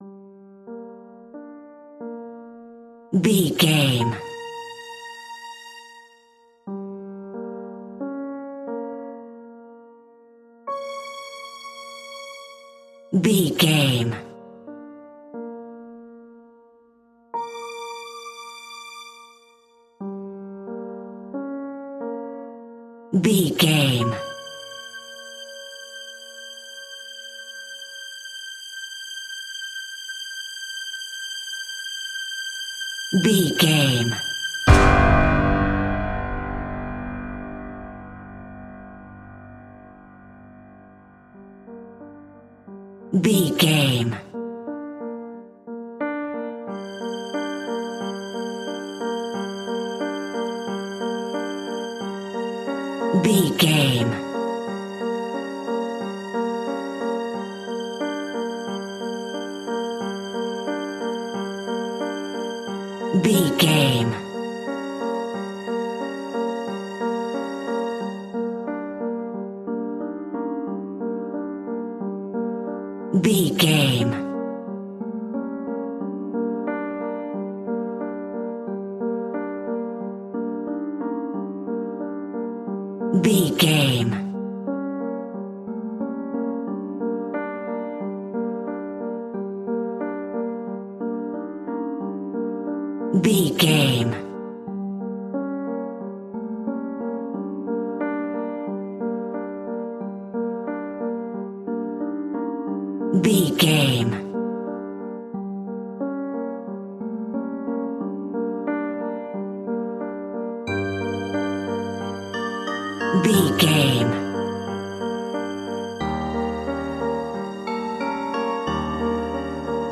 In-crescendo
Aeolian/Minor
scary
tension
ominous
dark
haunting
eerie
piano
strings
violin
horror piano
Scary Strings